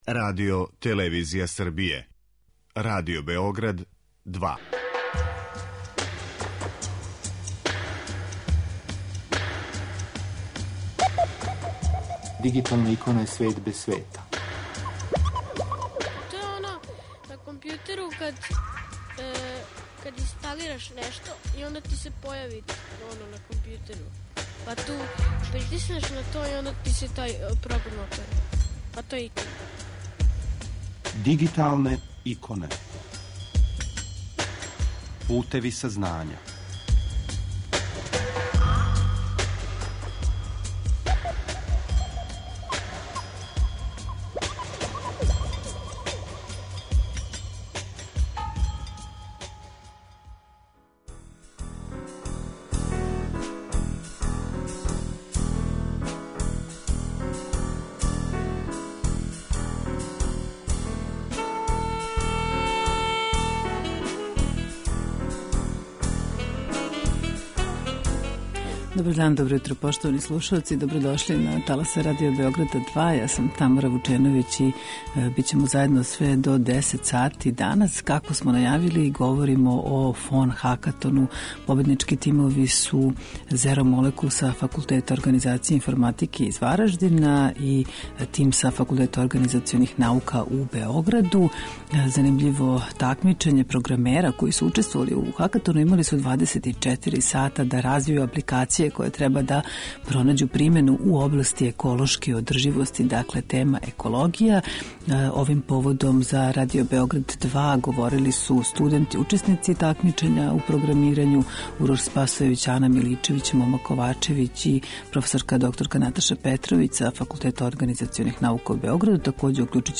Емисија Дигиталне иконе покренута je 2002. године, а емитује се сваког уторка на таласима Радио Београда 2 од 9 до 10 сати.